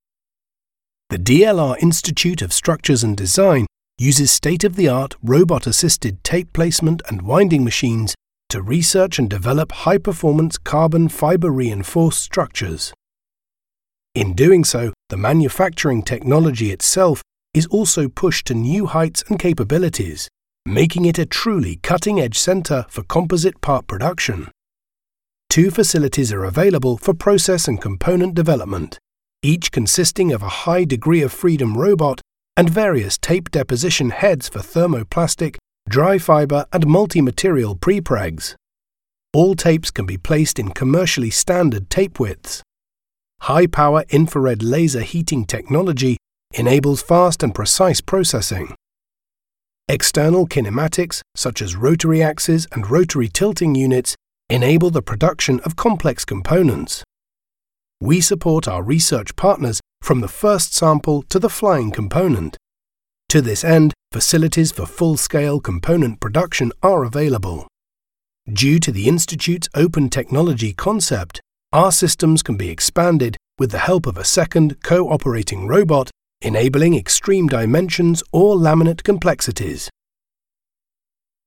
Anglais (Britannique)
Commerciale, Naturelle, Fiable, Chaude, Corporative
Corporate